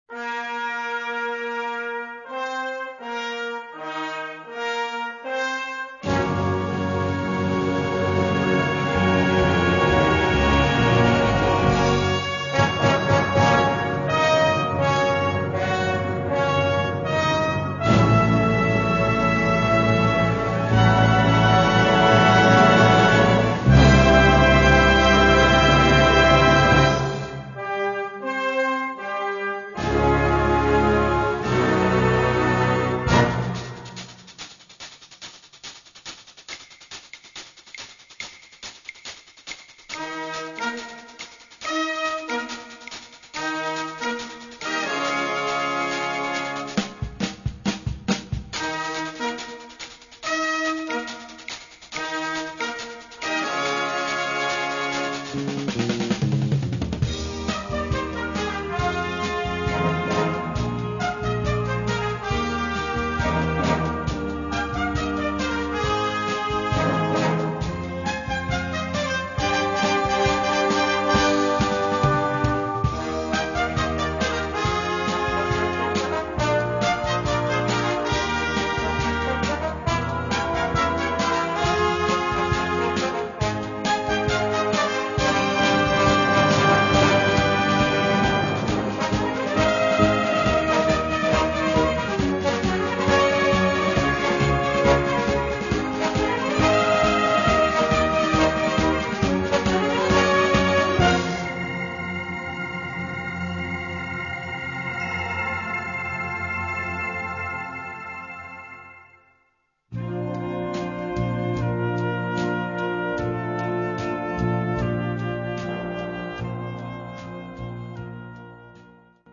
Categorie Harmonie/Fanfare/Brass-orkest
Subcategorie Ouverture (originele compositie)
Bezetting Ha (harmonieorkest)